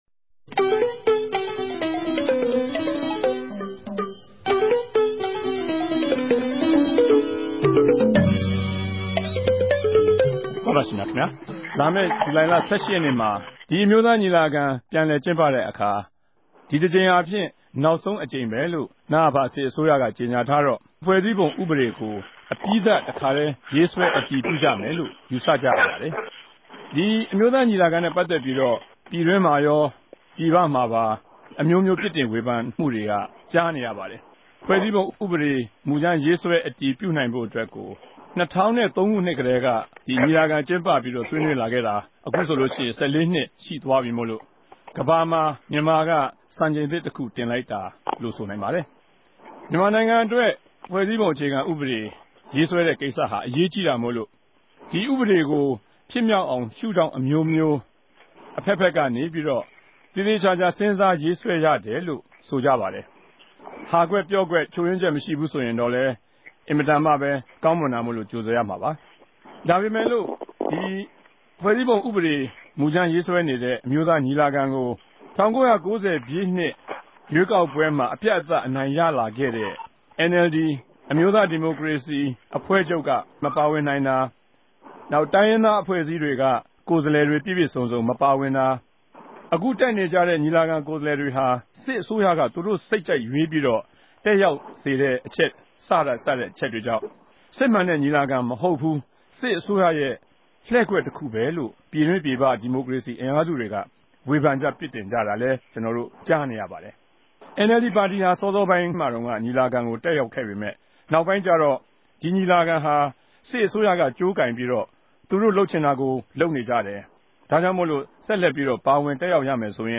RFA ဝၝရြင်တန်႟ုံးခဵြပ် စတူဒီယိုထဲကနေ
ဆြေးေိံြးပြဲစကားဝိုင်း